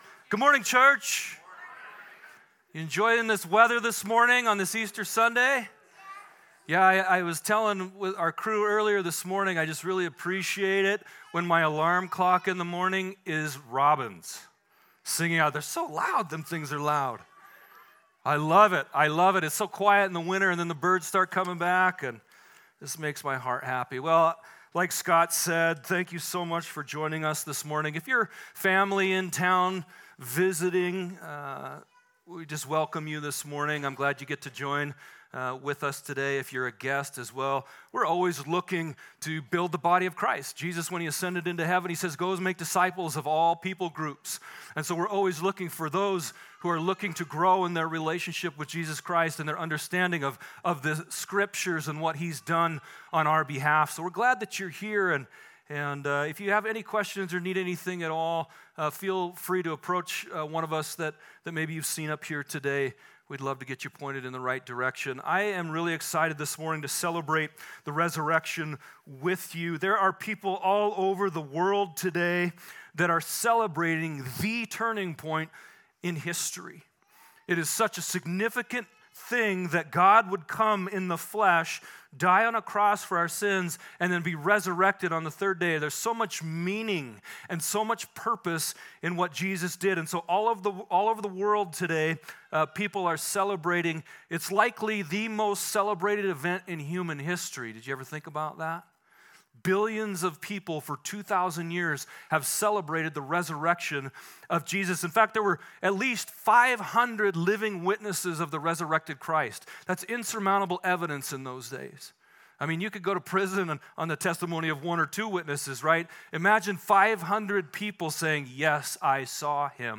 Easter Service